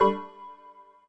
ploink.mp3